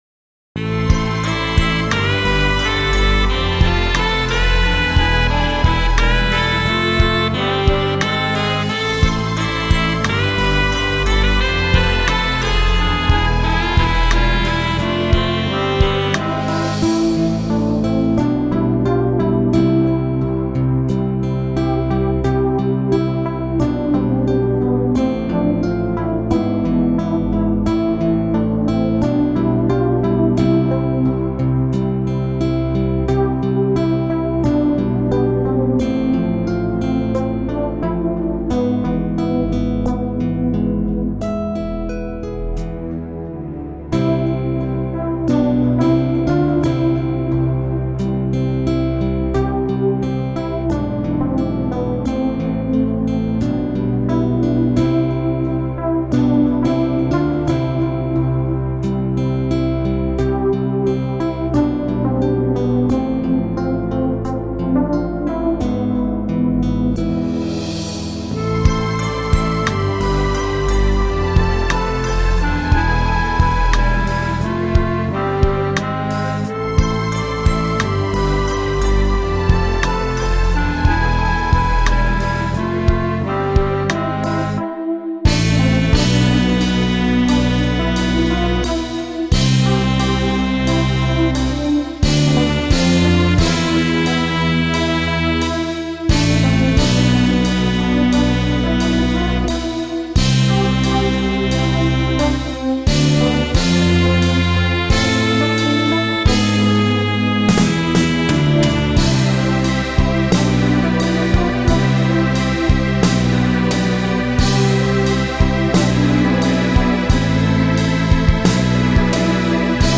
Качество:Студия